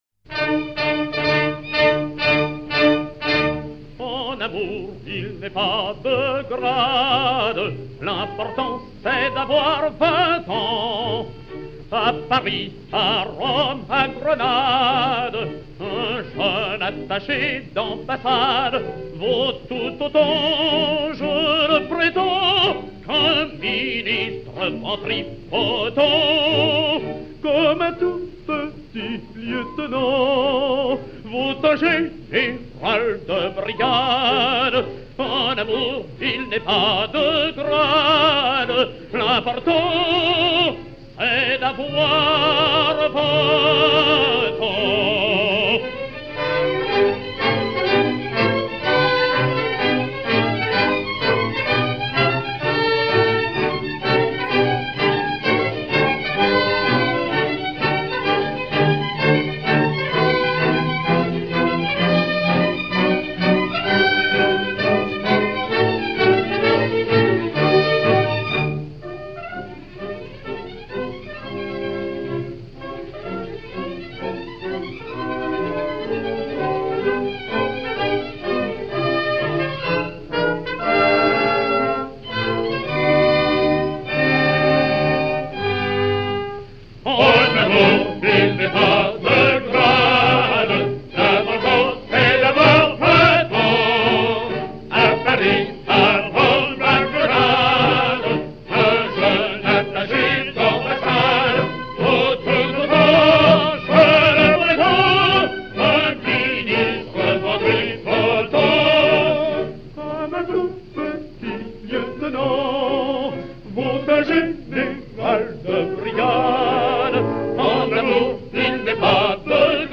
baryton français